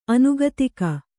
♪ anugatika